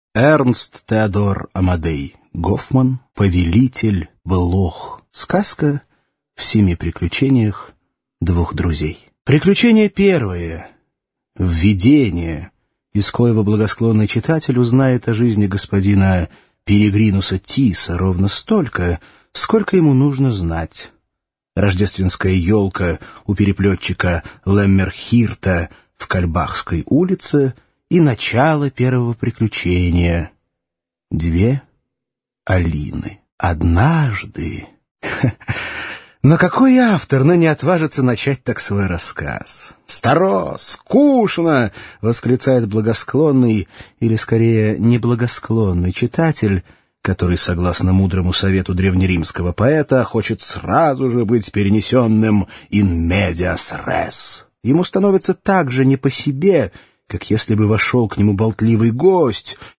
Аудиокнига Повелитель блох | Библиотека аудиокниг